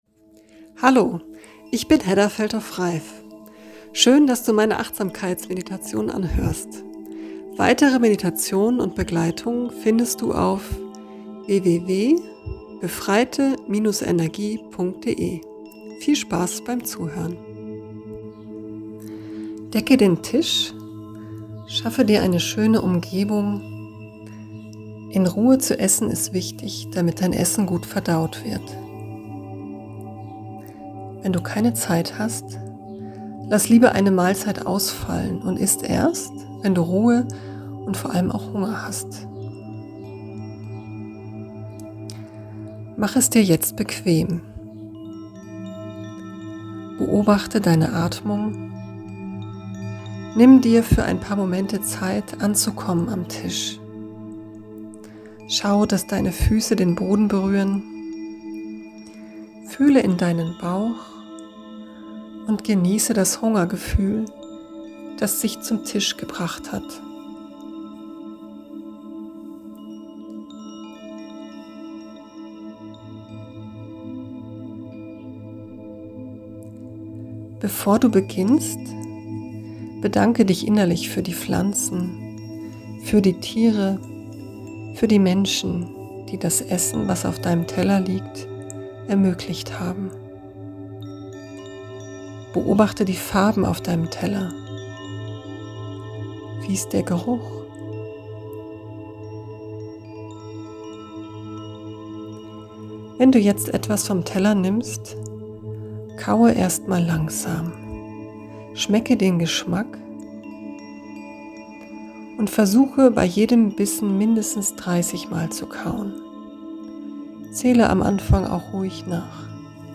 Meditation "Essen mit Genuss"